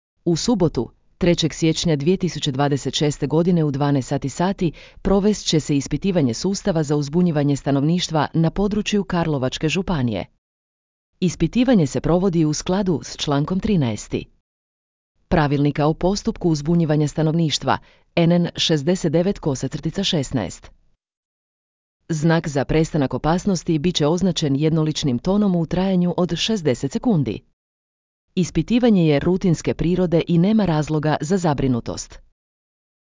Znak za prestanak opasnosti bit će označen jednoličnim tonom u trajanju od 60 sekundi.